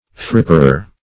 fripperer - definition of fripperer - synonyms, pronunciation, spelling from Free Dictionary Search Result for " fripperer" : The Collaborative International Dictionary of English v.0.48: Fripperer \Frip"per*er\, n. A fripper.